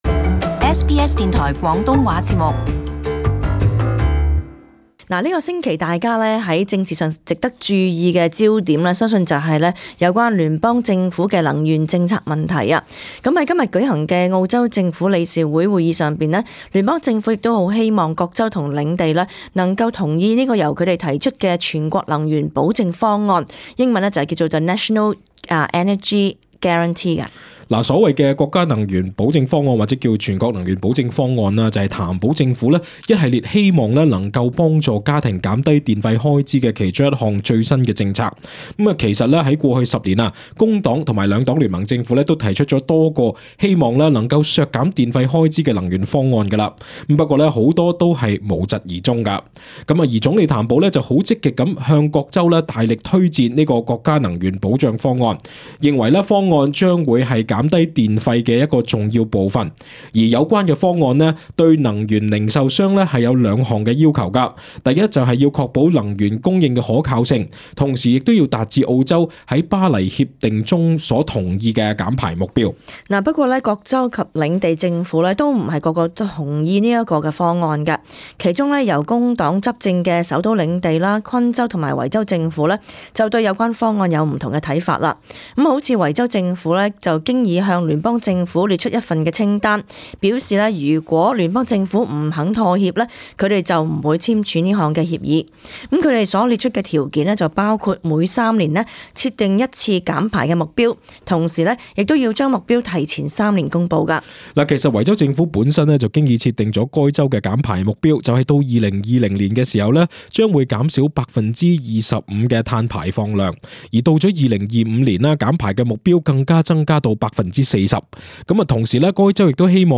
【時事報導】 聯邦能源部長警告維州不要反對全國能源保證方案